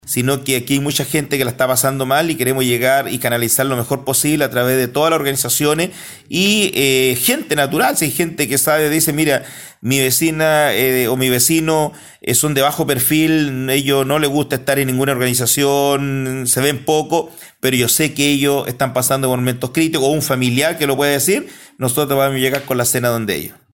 Así lo explicó el alcalde Cristian Tapia: